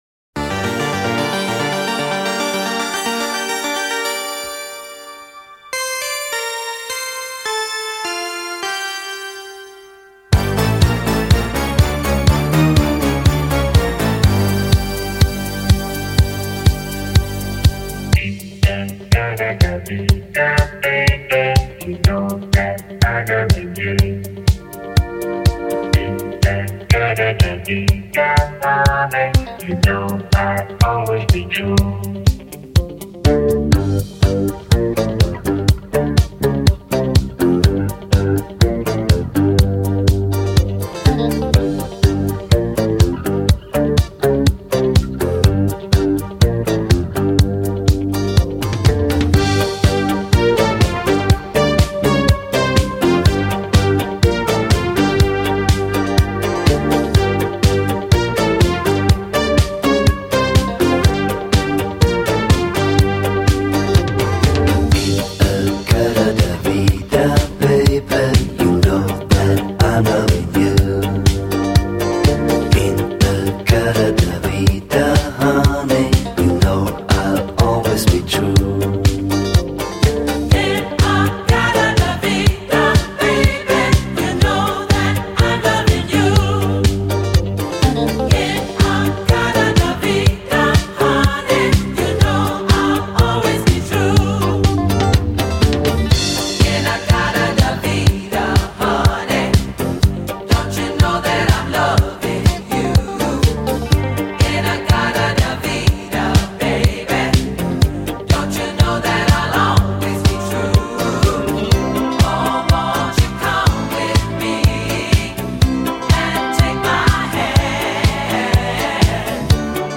A forgotten masterpiece of electronic dance music from 1980.